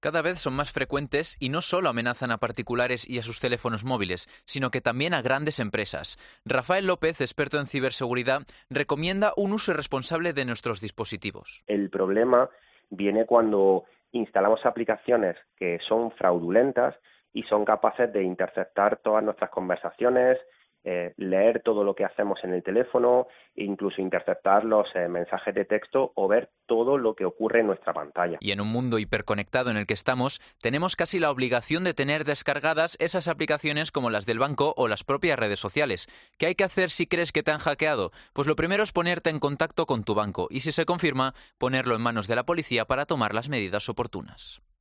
habla con expertos en ciberseguridad para prevenir los ataques de los hackers